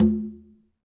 Quinto-HitN_v2_rr2_Sum.wav